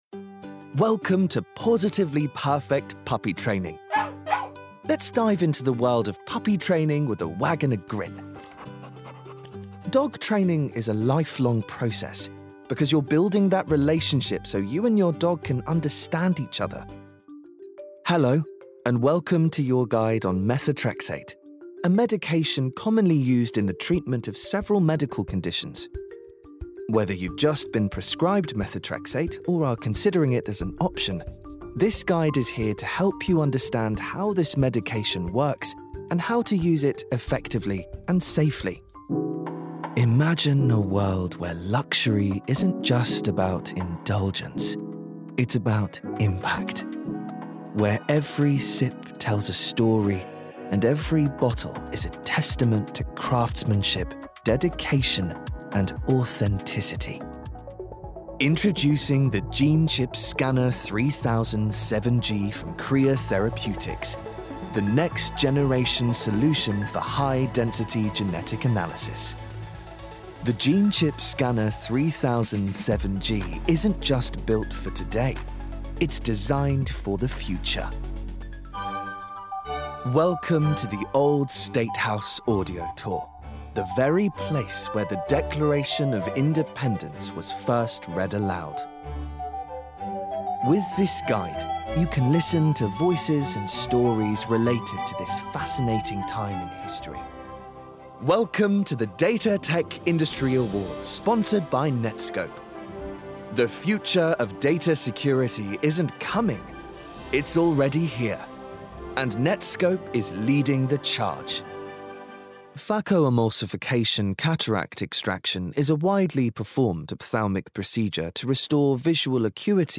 Corporate Showreel
His approachable, youthful British RP voice has roots in Yorkshire, East Midlands, and Essex.
Male
Neutral British
Friendly